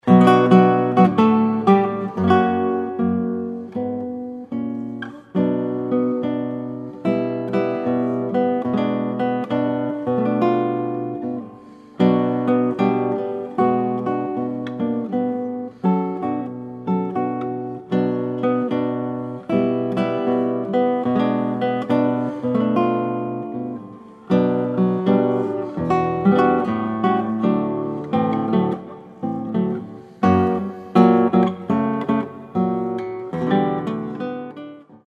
solo guitar